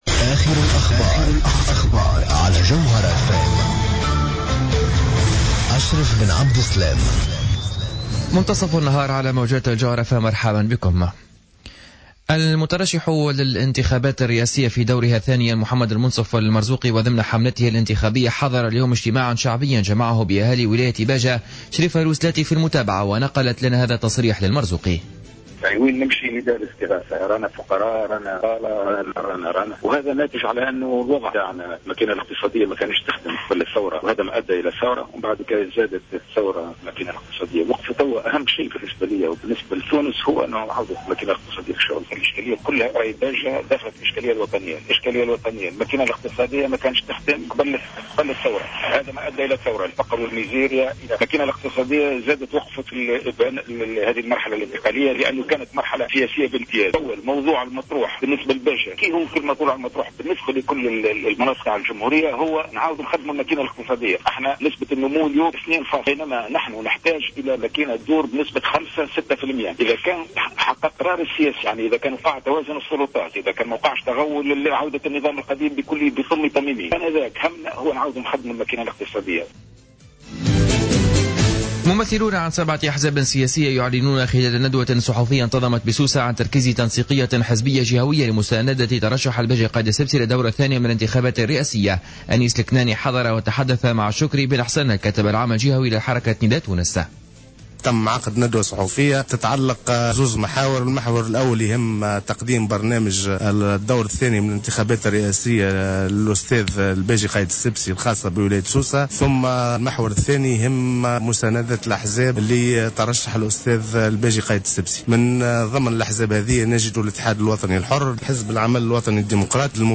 نشرة أخبار منتصف النهار ليوم الجمعة 12-12-14